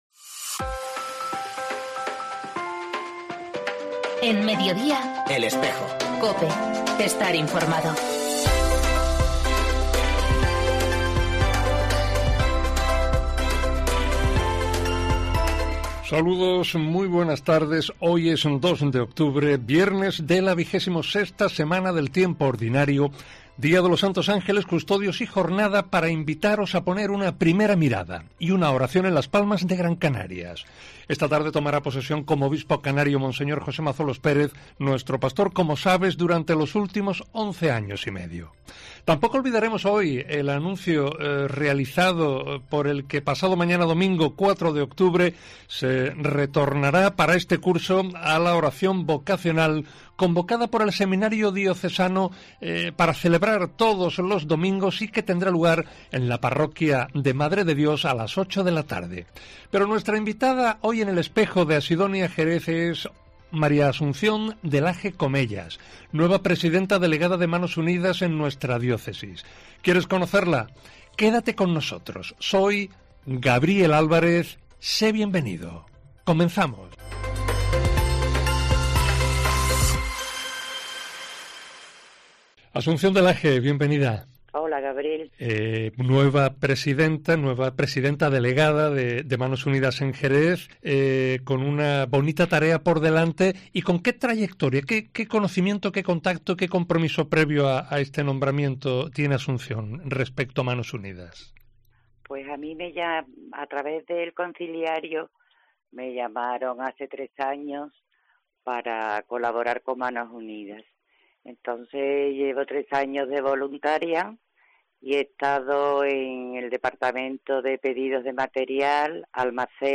PROGRAMACIÓN SOCIO-RELIGIOSA